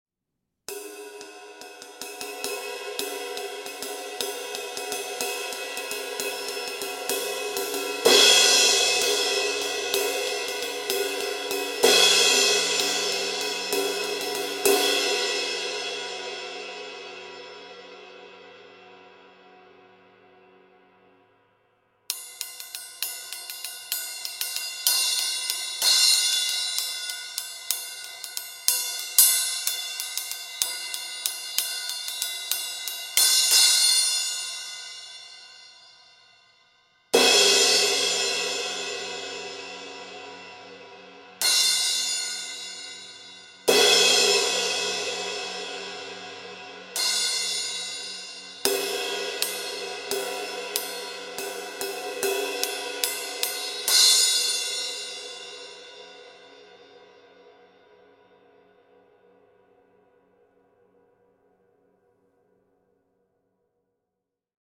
Here’s how the Praxis cymbals sound recorded side by side with our Heartbeat Classic series cymbals:
Compare Heartbeat Classic 20″ ride to Praxis 20″ ride: